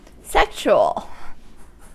Ääntäminen
US GenAm: IPA : /ˈsɛkʃuəl/